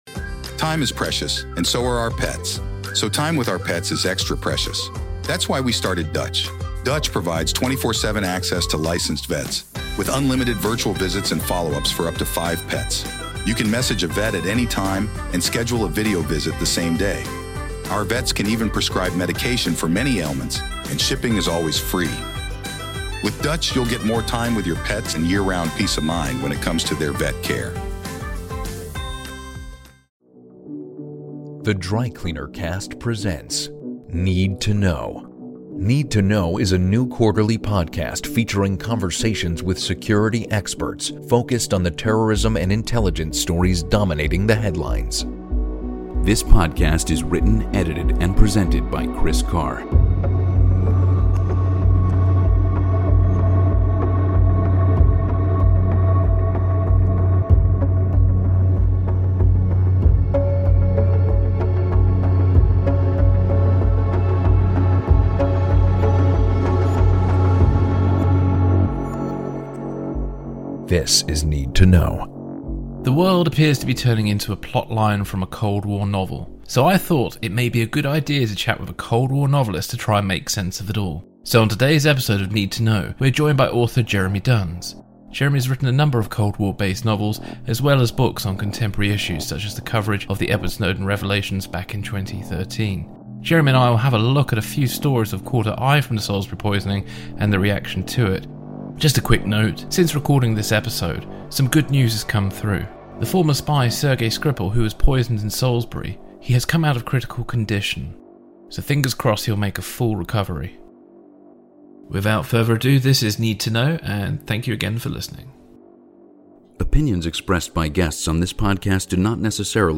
The world is turning into a plot line from a Cold War novel, so we thought that it may be a good idea to chat with a Cold War novelist to try and make sense of it all.